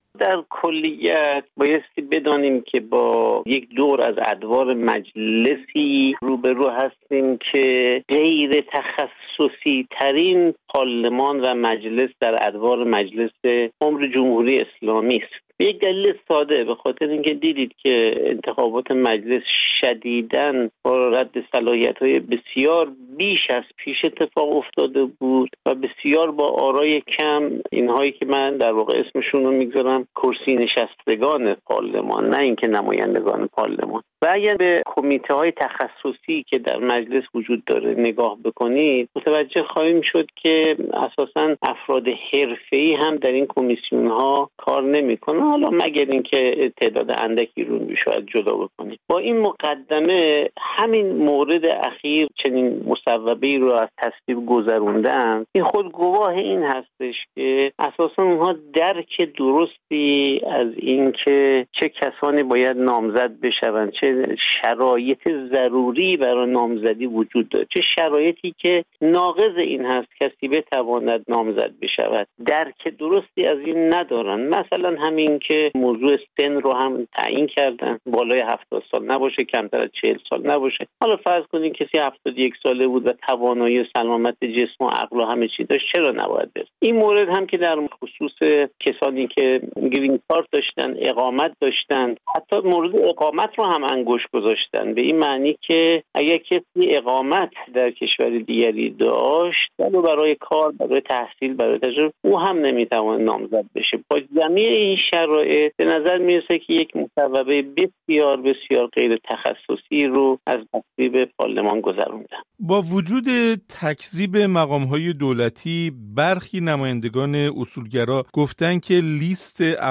در گفت‌وگویی